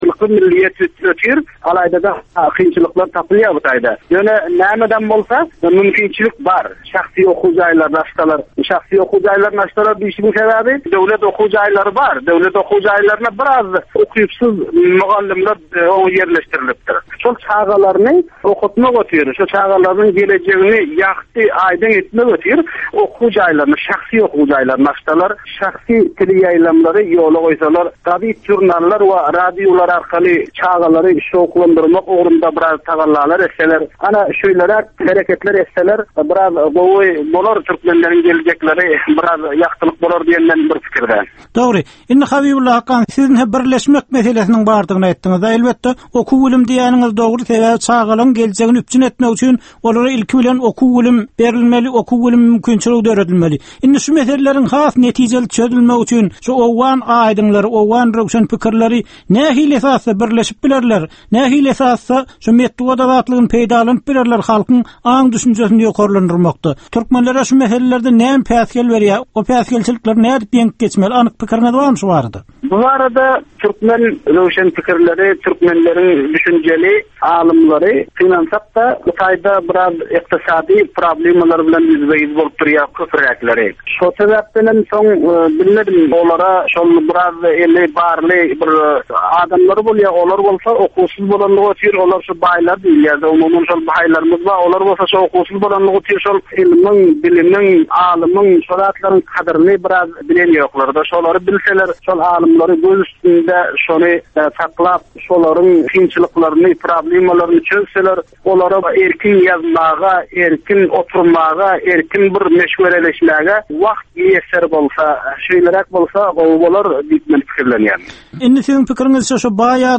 Türkmen jemgyýetindäki döwrüň meseleleri. Döwrüň anyk bir meselesi barada 10 minutlyk ýörite syn-gepleşik. Bu gepleşikde diňleýjiler, synçylar we bilermenler döwrüň anyk bir meselesi barada pikir öwürýärler, öz garaýyşlaryny we tekliplerini orta atýarlar.